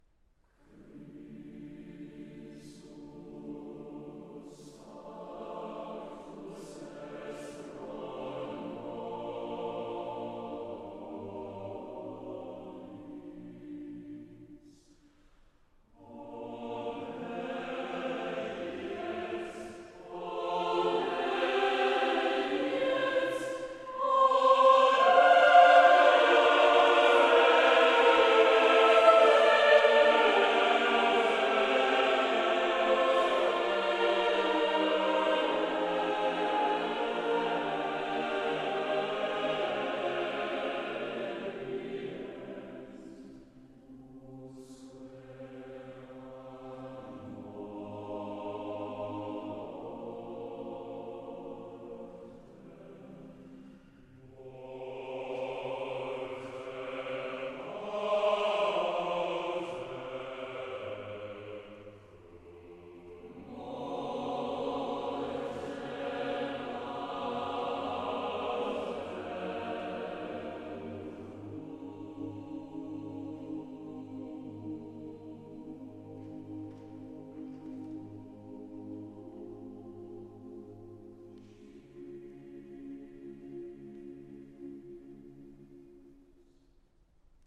Bruckner Graduale Christus factus est pro nobis (uit de motetten)  Melodisch en harmonisch ritme
Uitgevoerd door The Choir of Trinity College Cambridge.